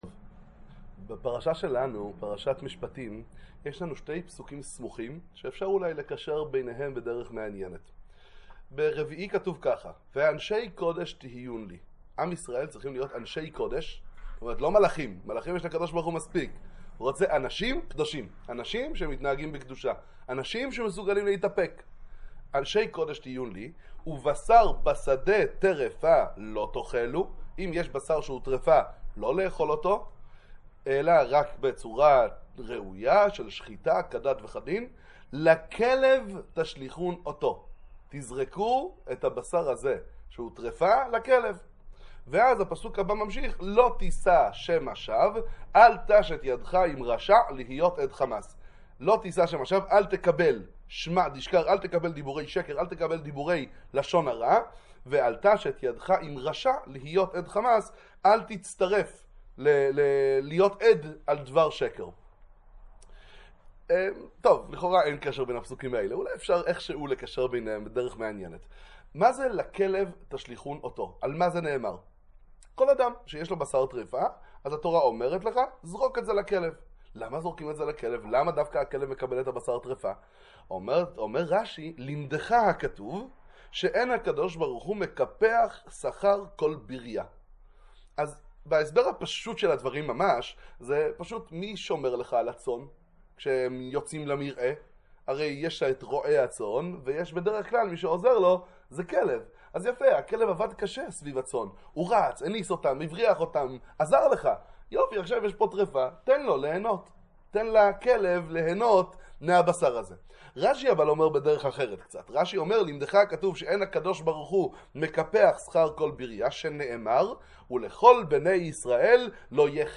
דבר תורה קצר לשולחן שבת, שיעור תורה על פרשת השבוע